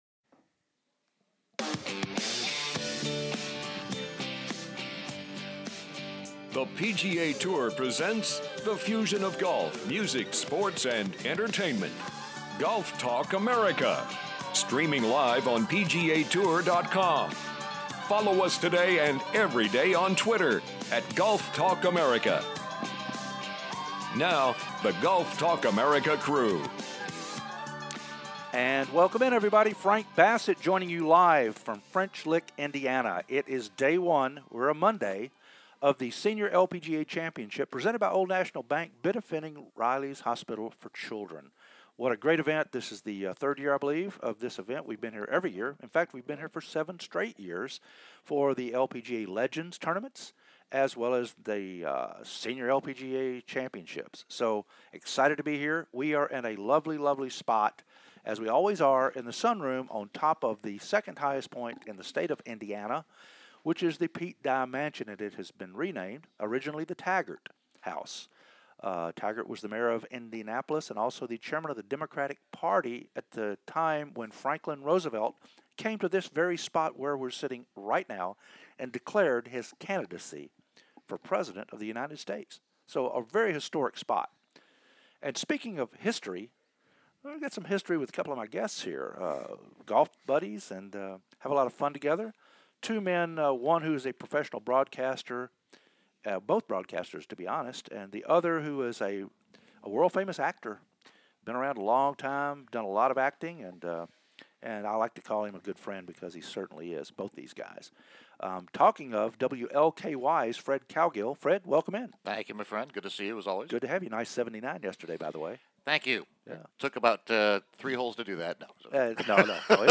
Jerry Foltz From The Golf Channel "LIVE" At The Sr. LPGA Championship